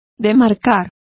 Complete with pronunciation of the translation of demarcate.